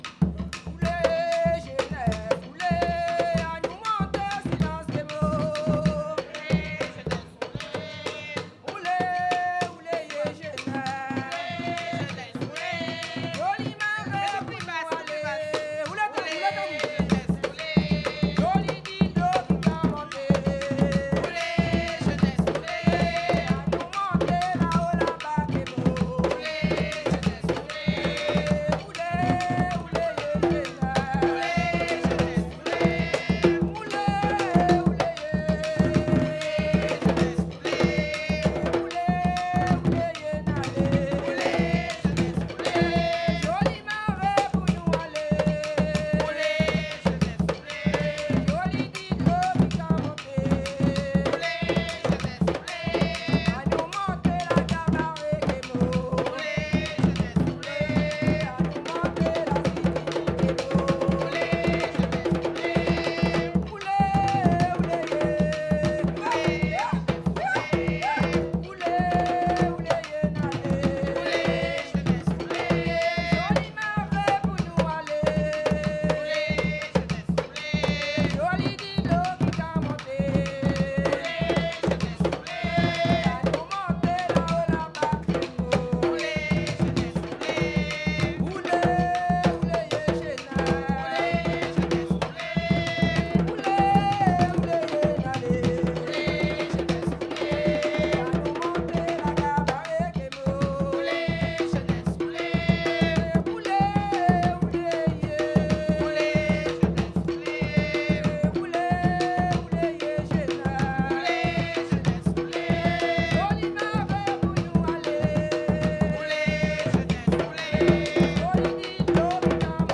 danse : kasékò (créole)
Pièce musicale inédite